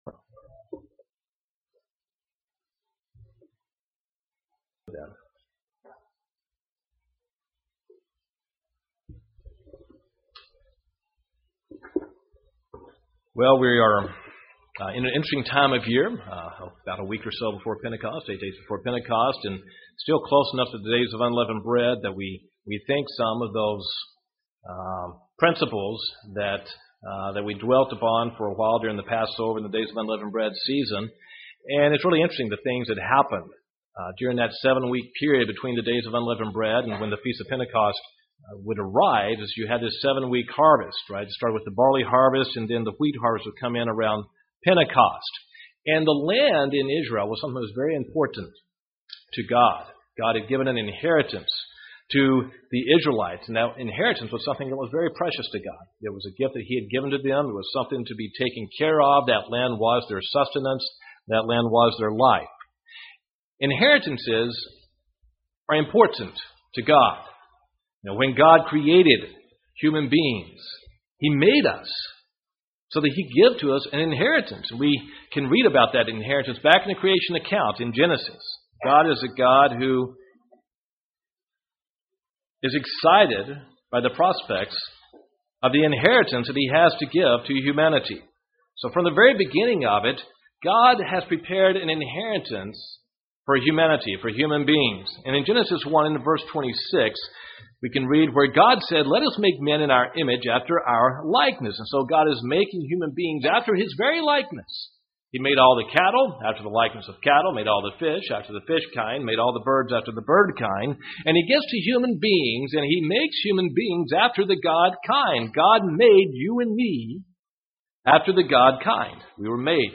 Sermons
Given in Gadsden, AL Huntsville, AL